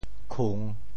潮州拼音“kung3”的详细信息
潮州府城POJ khùng
khung3.mp3